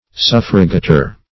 Search Result for " suffragator" : The Collaborative International Dictionary of English v.0.48: Suffragator \Suf"fra*ga`tor\, n. [L.] One who assists or favors by his vote.